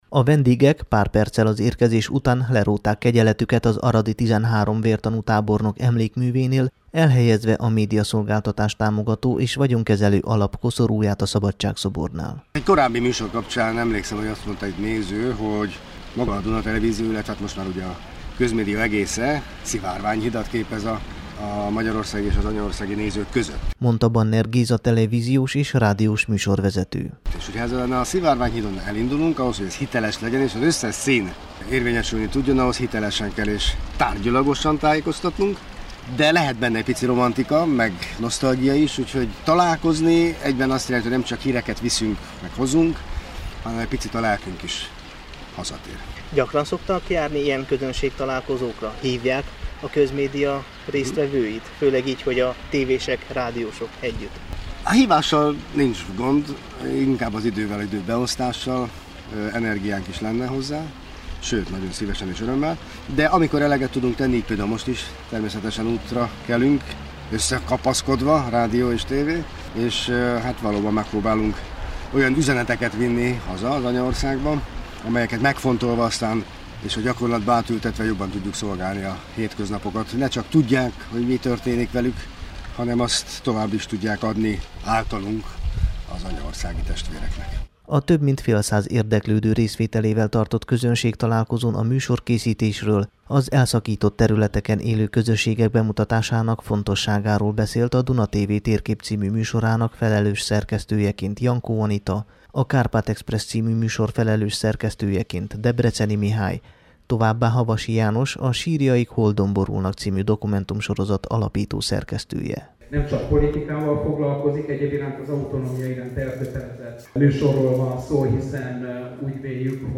Az Aradi Magyar Napok keretében a Csiky Gergely Főgimnáziumban tartott közönségtalálkozóra a Duna TV, a Magyar Televízió és a Kossuth Rádió munkatársai, szerkesztői és műsorvezetői jöttek el az Aradi Hírek meghívására. Itt meghallgatható a rendezvény beszámolója, ami a Temesvári Rádió számára készült.